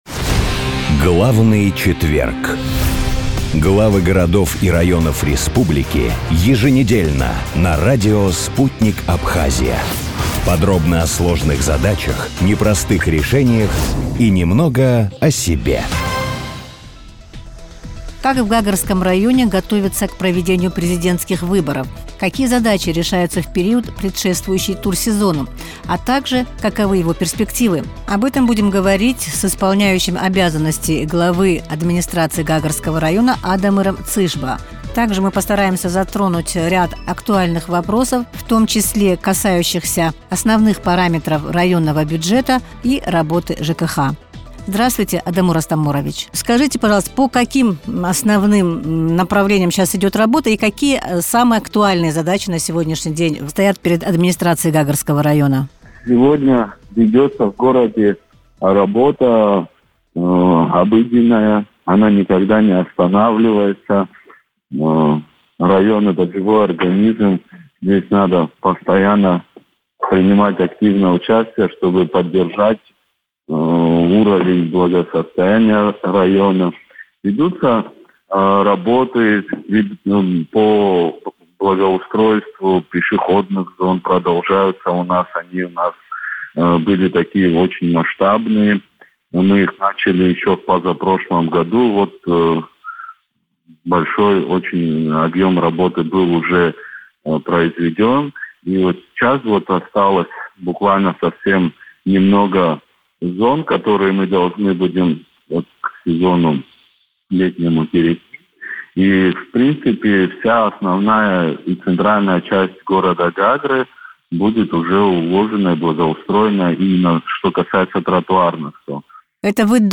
Исполняющий обязанности главы администрации Гагрского района Адамур Цишба в интервью радио Sputnik рассказал, как проходит подготовка к летнему турсезону и насколько район готов к проведению голосования.